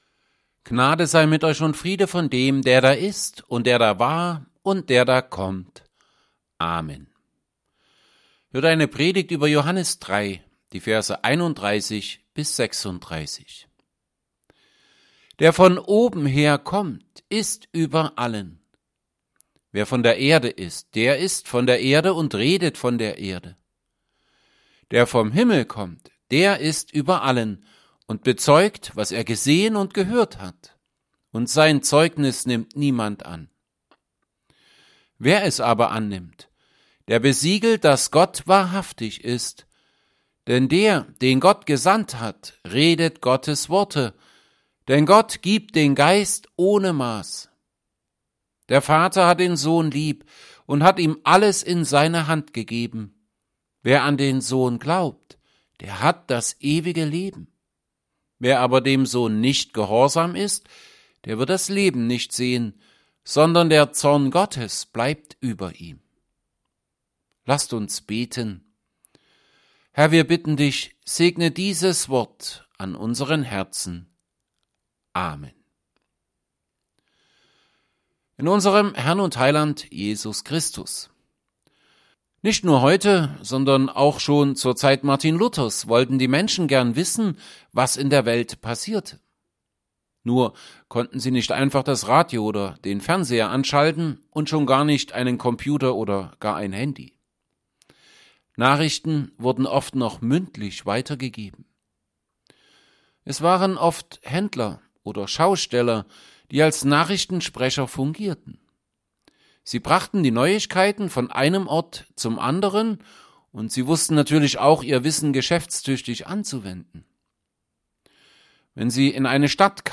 Predigt_zu_Johannes_3_31b36.mp3